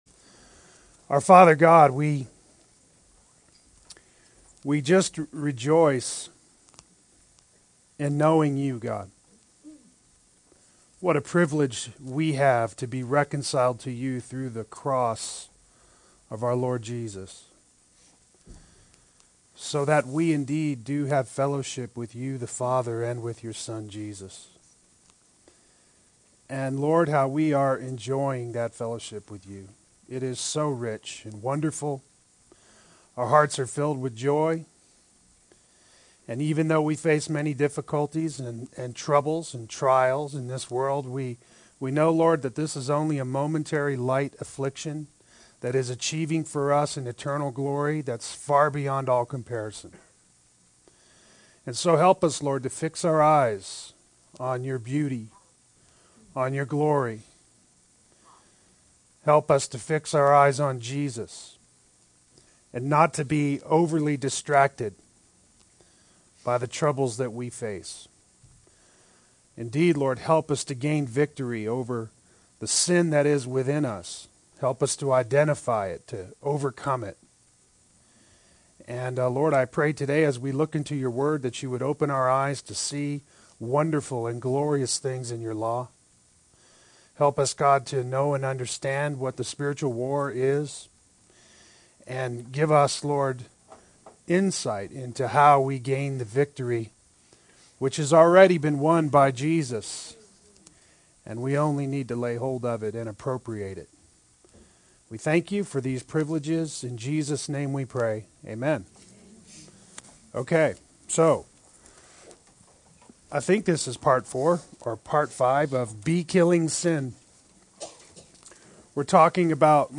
Play Sermon Get HCF Teaching Automatically.
Part 4 Adult Sunday School